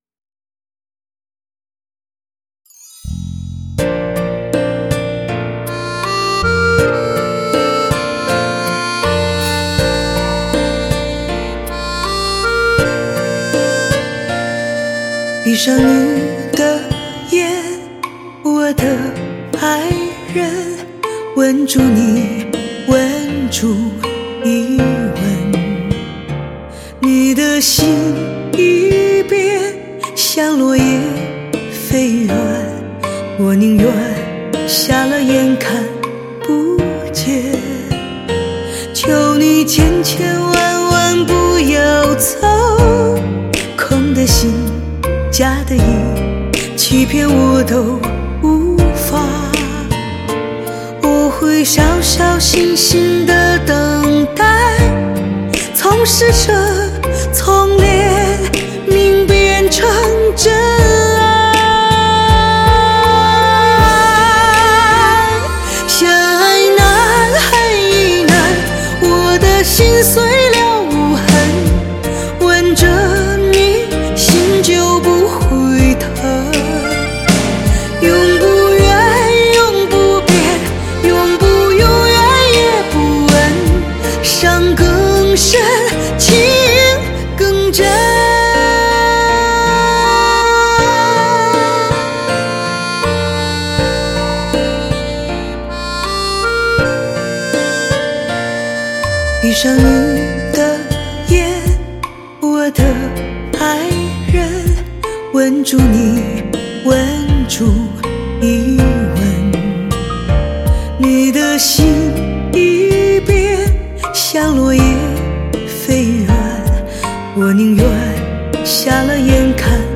★被誉为——抚平心灵创伤的音乐 “史上最令人期待的治愈系女声”
极致完美的接近原始母带的超原音音质的再现